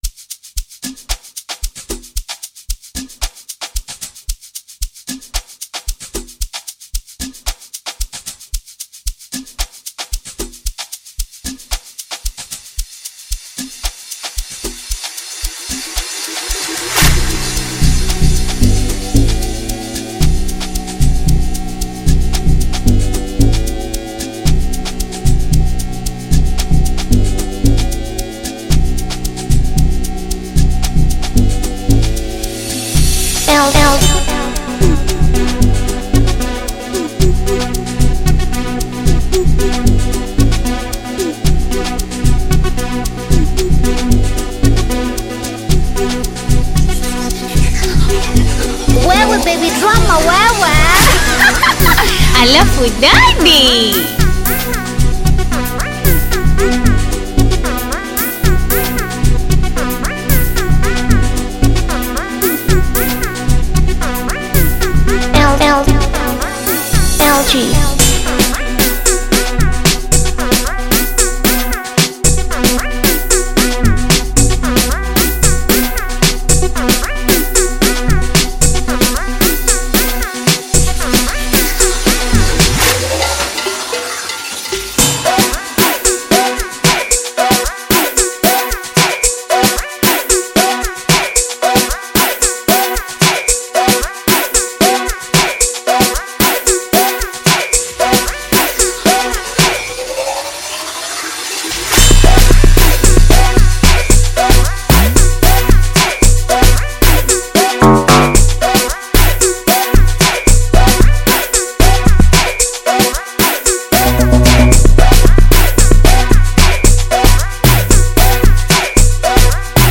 Bongo Flava music track
Bongo Flava